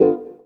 137 GTR 3 -R.wav